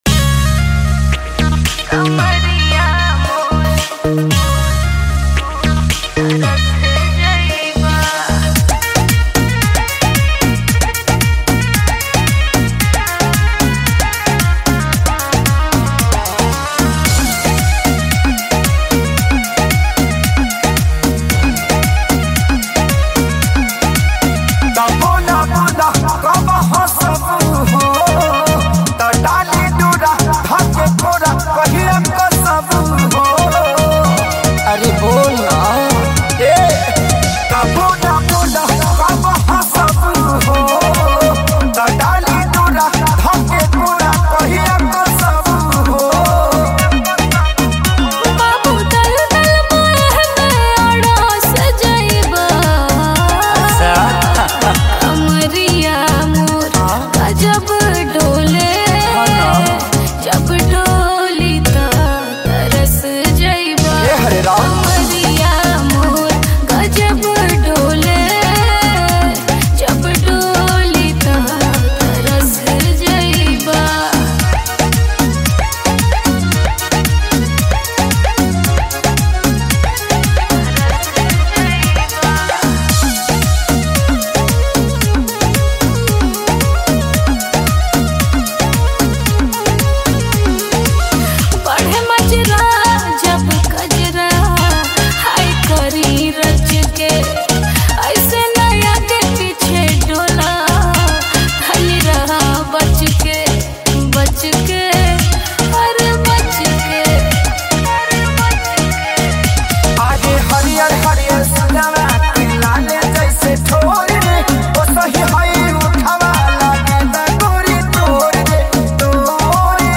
Category: New Bhojpuri Mp3 Songs